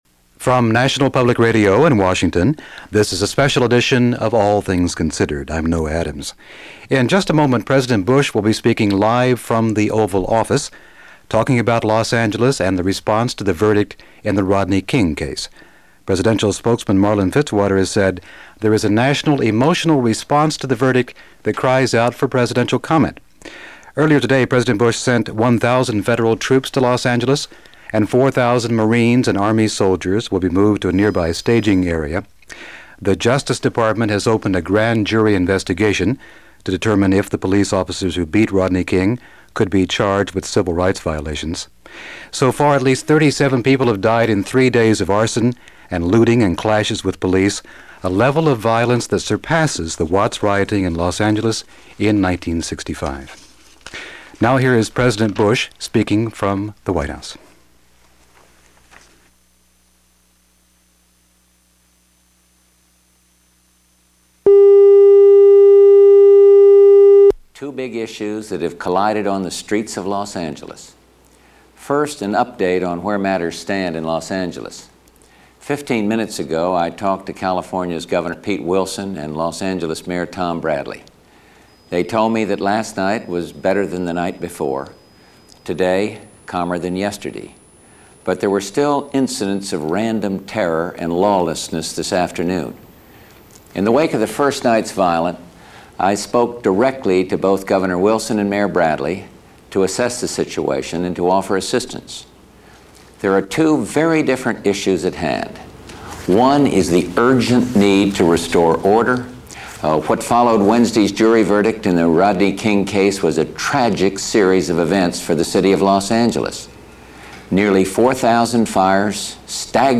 – Pres. Bush-Mayor Bradley – News for May 1, 1992